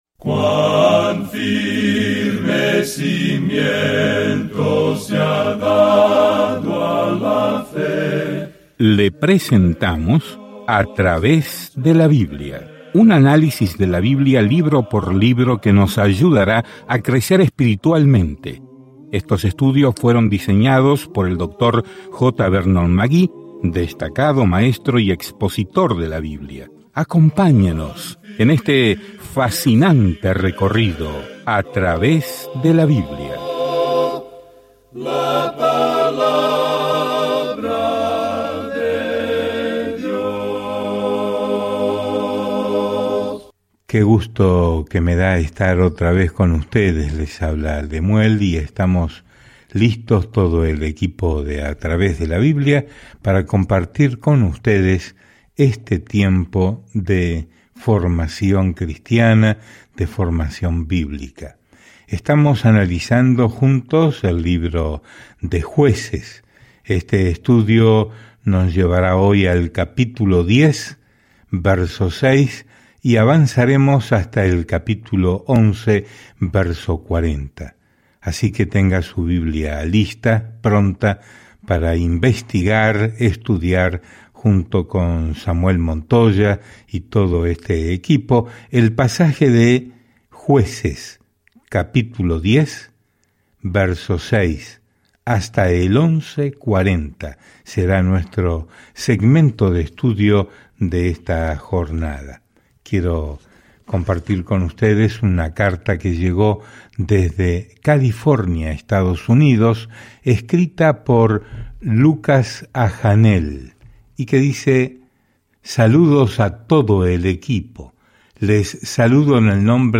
Escrituras Jueces 10:6-18 Jueces 11 Día 7 Comenzar este Plan Día 9 Acerca de este Plan Jueces registra las vidas a veces retorcidas y al revés de las personas que se están adaptando a sus nuevas vidas en Israel. Viaja diariamente a través de Jueces mientras escuchas el estudio de audio y lees versículos seleccionados de la palabra de Dios.